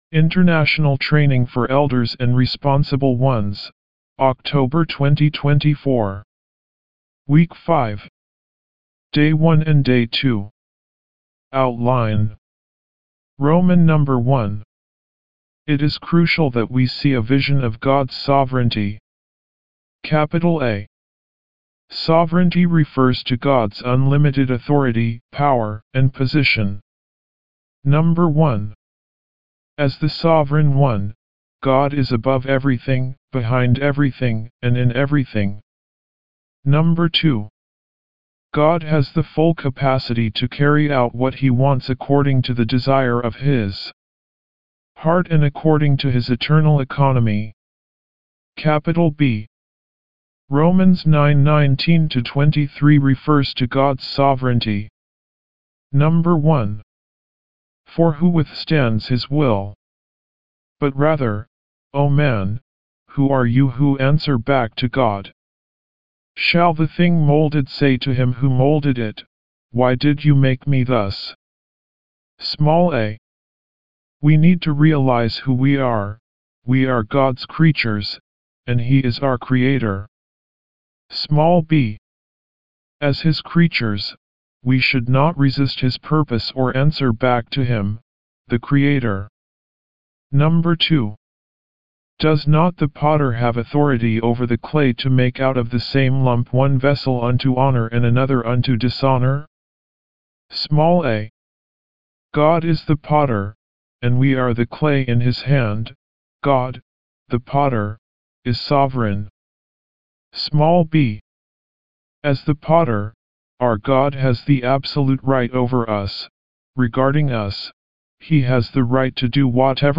W5  Outline Recite
D1 English Rcite：